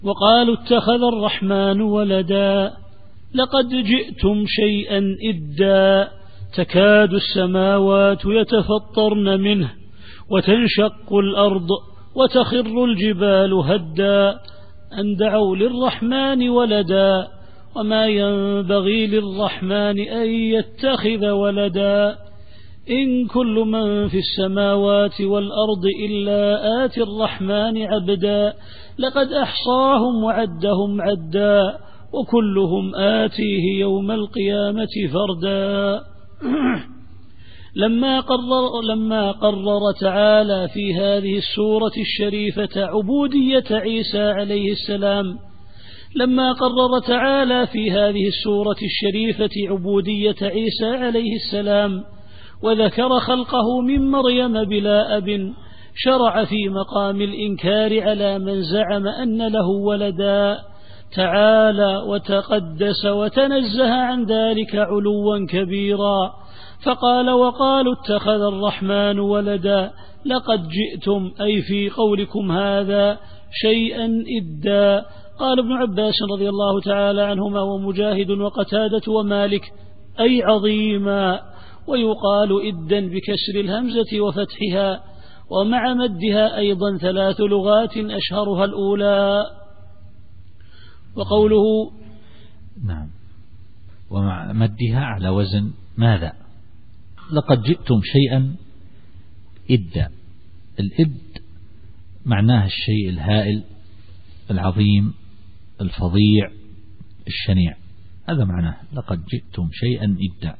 التفسير الصوتي [مريم / 88]